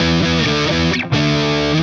AM_HeroGuitar_130-E01.wav